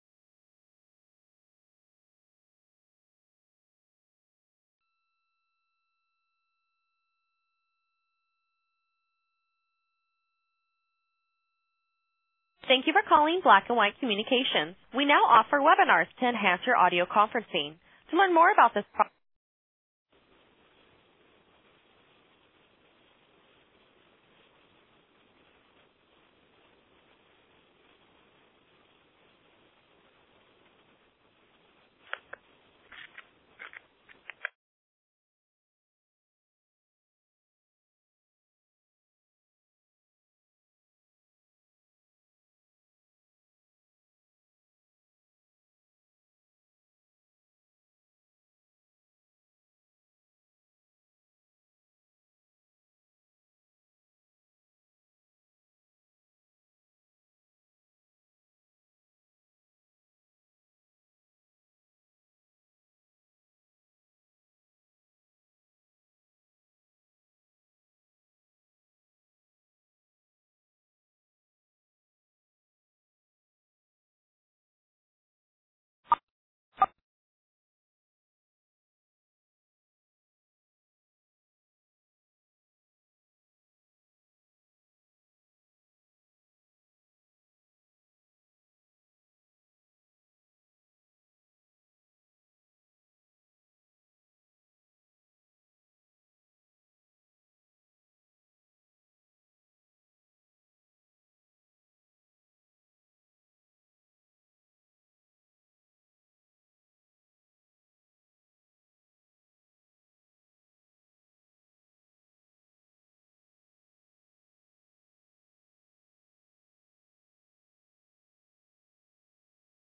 We are currently editing the replays to eliminate the excessive amount of dead air. This is the first time we have worked with this specific recording program so we have experienced some technical issues.